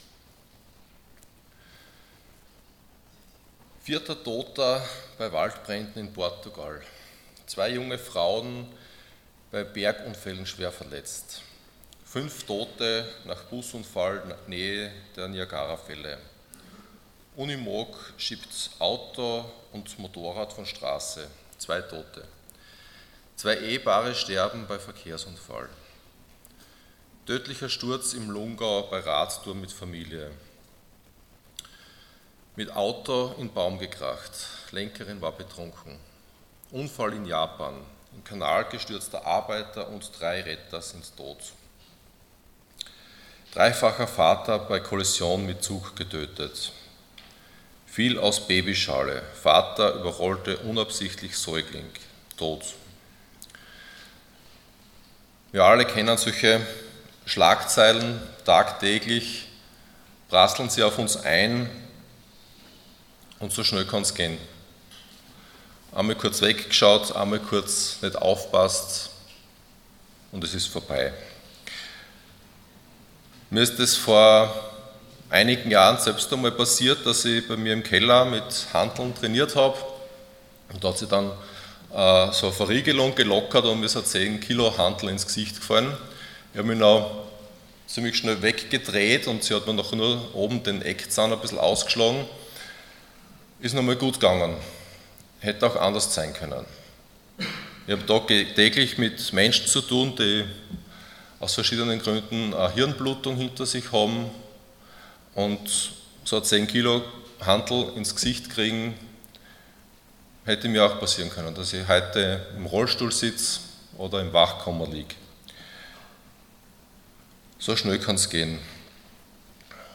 Passage: Jakobus 4,13-17 Dienstart: Sonntag Morgen Deo volente